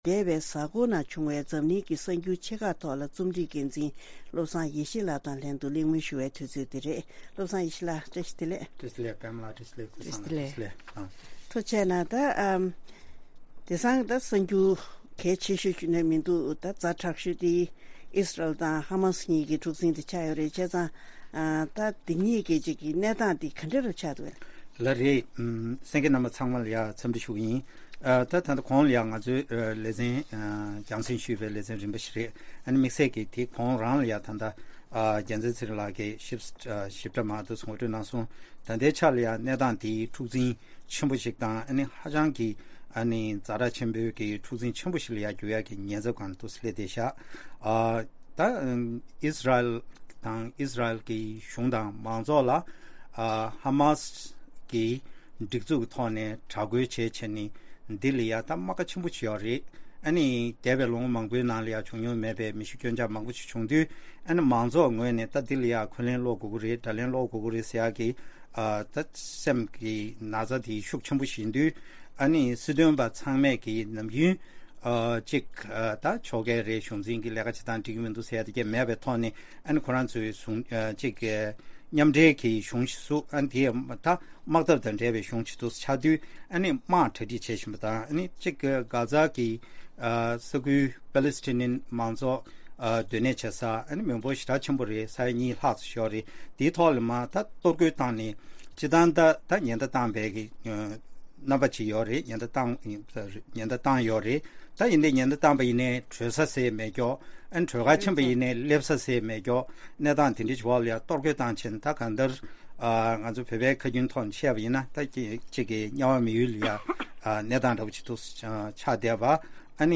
འཛམ་གླིང་གི་གསར་འགྱུར་ཆེ་ཁག་ཐོག་རྩོམ་བྲིས་འགན་འཛིན་དང་ལྷན་གླེང་མོལ།